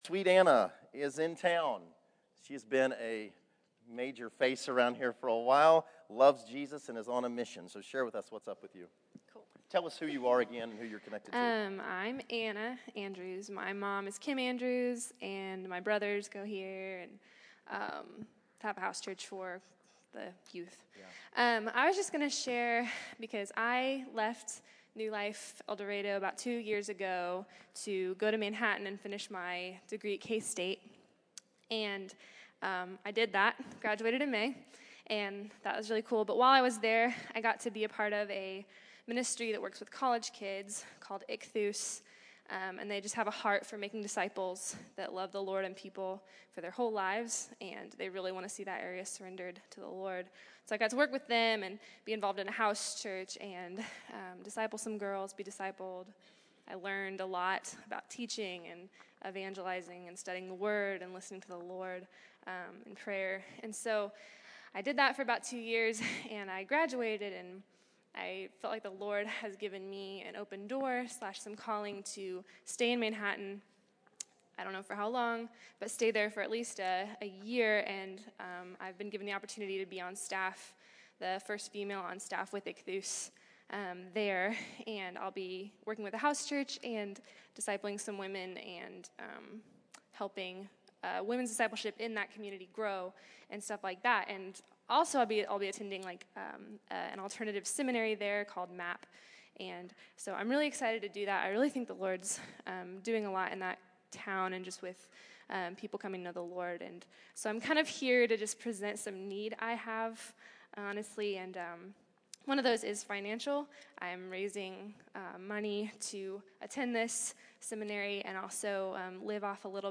August 17, 2014      Category: Testimonies      |      Location: El Dorado